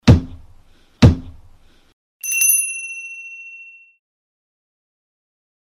Son_cloche_debut.mp3